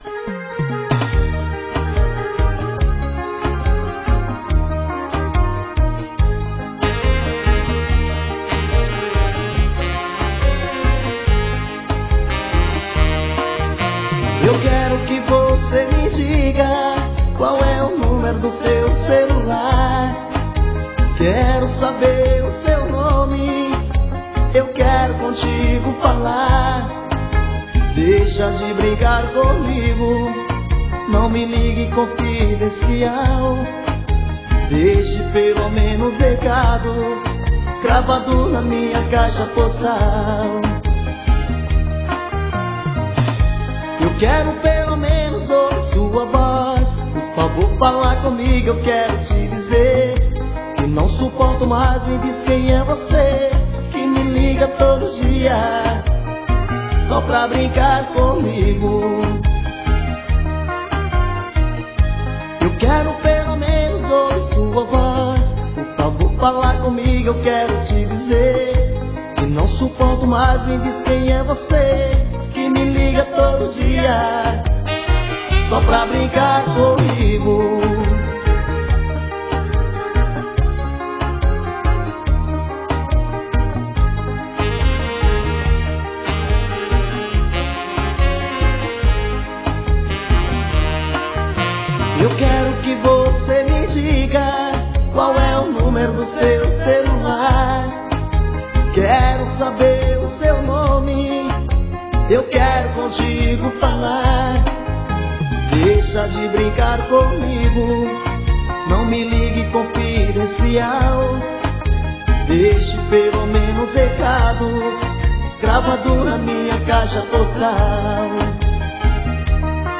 Arrocha.